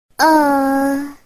女声为难呃一声音效免费音频素材下载